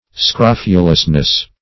Scrof"u*lous*ness, n.